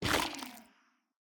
Minecraft Version Minecraft Version 1.21.5 Latest Release | Latest Snapshot 1.21.5 / assets / minecraft / sounds / block / sculk / break10.ogg Compare With Compare With Latest Release | Latest Snapshot